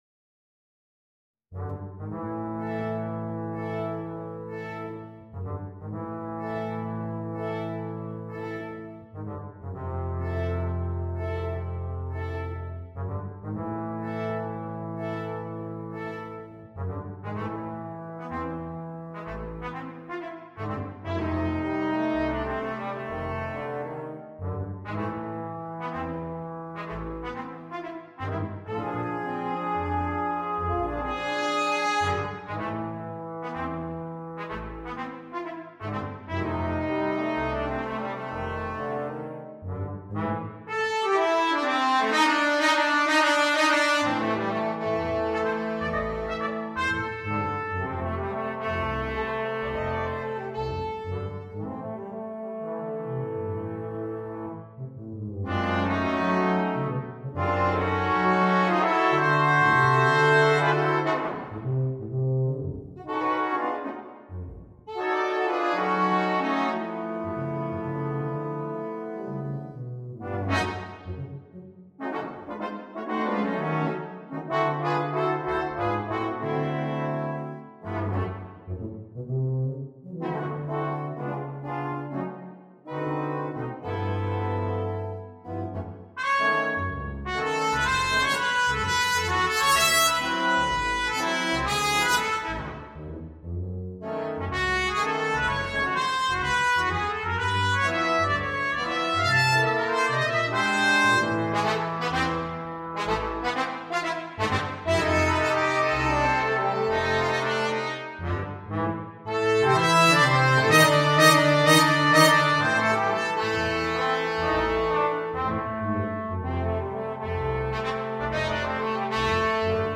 для брасс-квинтета.